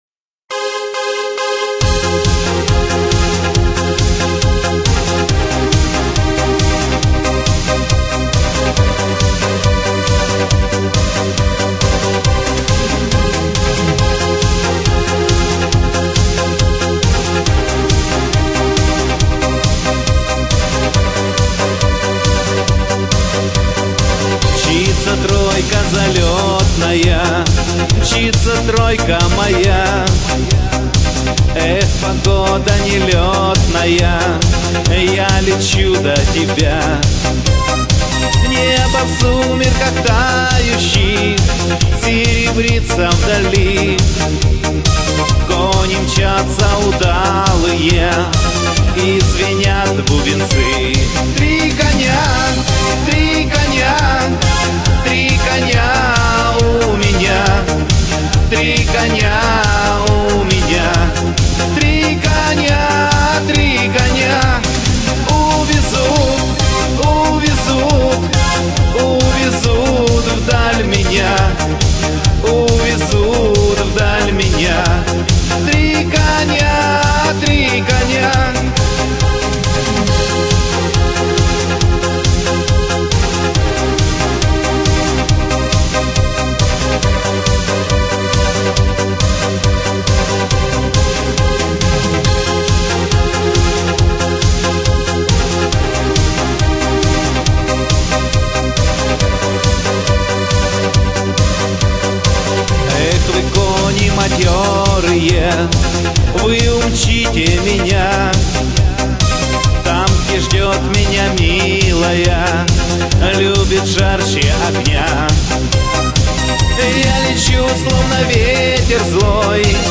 музыка шансон